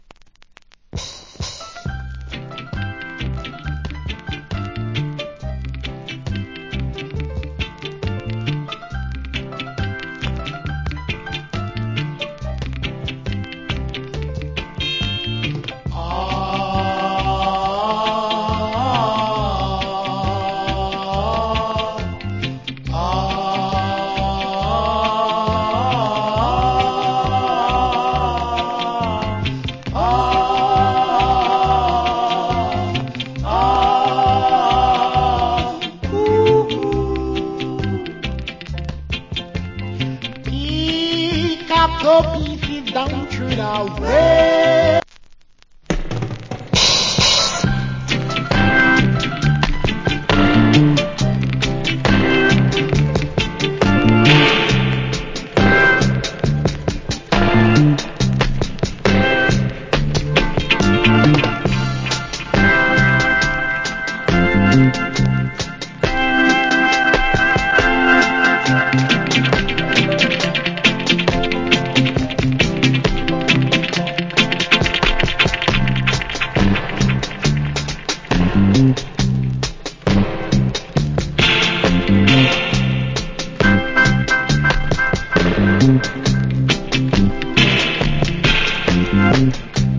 Cool Roots Rock Vocal.